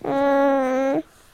grunts.ogg